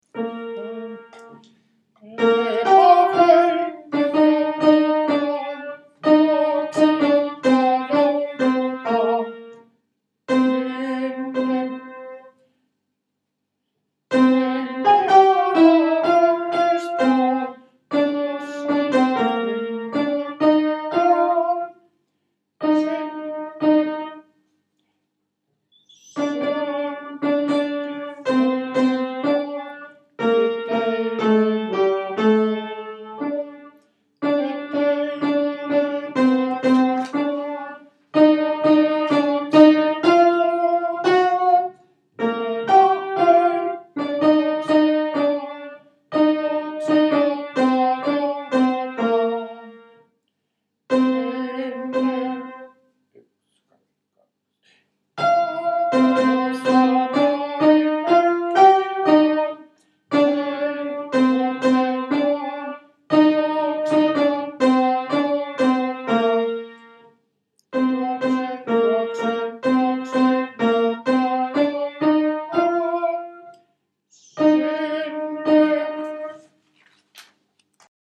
Matkalla_1_tenori_ylempi.mp3